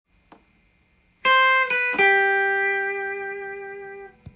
エレキギターで弾ける【Gミクソリディアンフレーズ集】オリジナルtab譜つくってみました
非常に明るい響きがするミクソリディアンスケールですが
①のフレーズは、メジャースケールでも頻繁にメロディーラインやソロで使われる
ドシソの並びです。